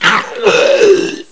PSP/CTR: Also make weapon and zombie sounds 8bit